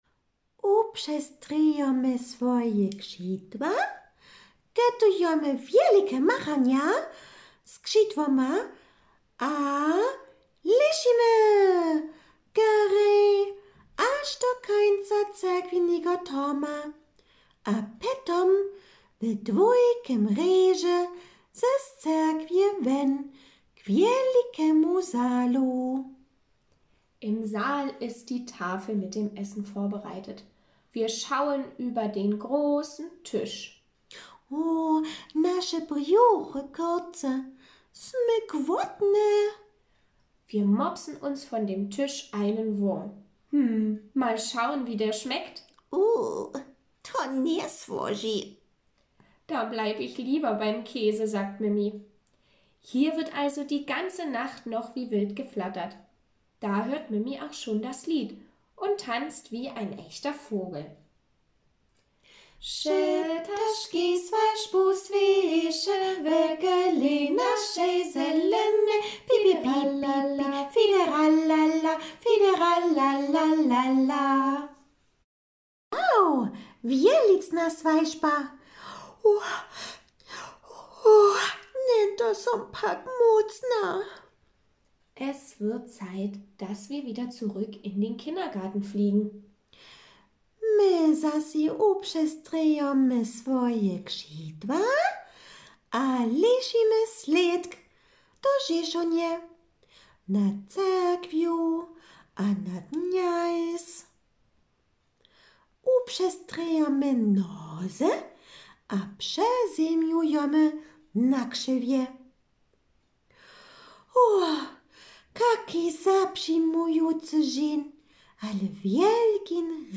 Pógibowańske tšojeńko:
02 BewegGeschichte_VH_Mimi feiert VH_2.mp3